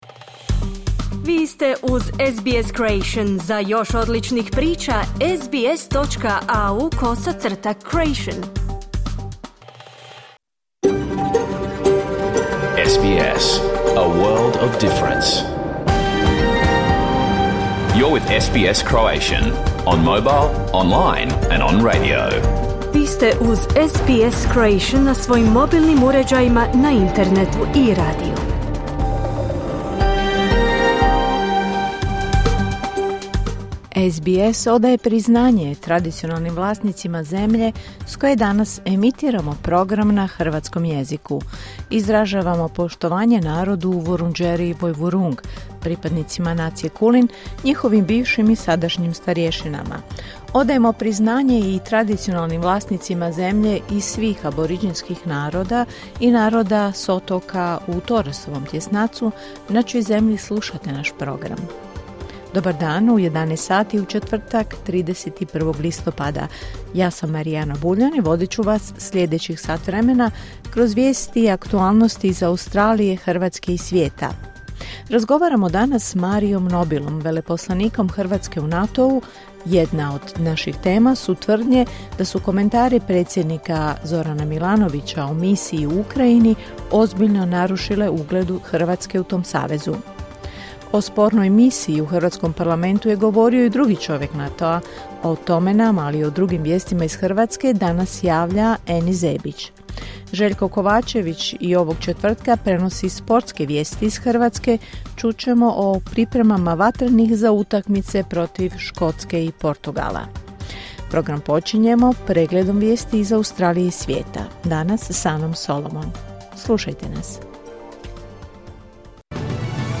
Vijesti i aktualnosti iz Australije, Hrvatske i ostatka svijeta. Emitirano uživo na radiju SBS1 u četvrtak, 31. listopada s početkom u 11 sati po istočnoaustralskom vremenu.